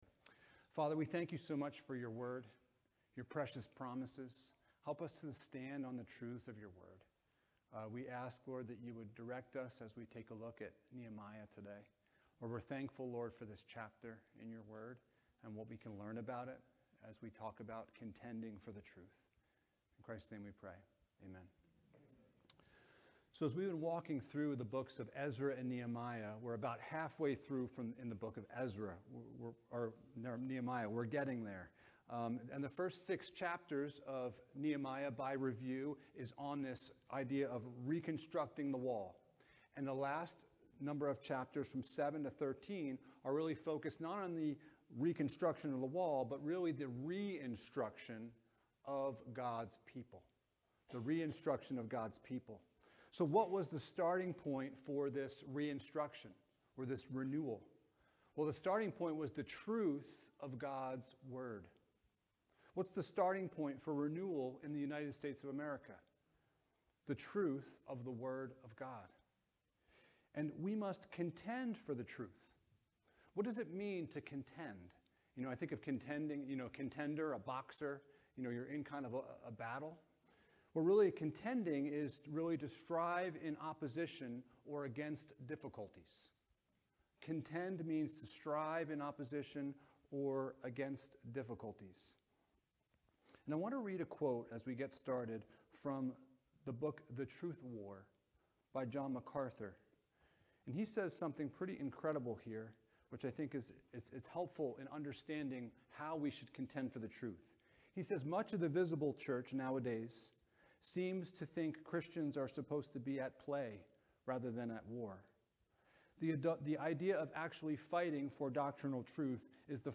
Renovation of the Heart Passage: Nehemiah 8: 1-18 Service Type: Sunday Morning « Serving One Another in Love Rules